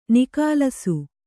♪ nikālasu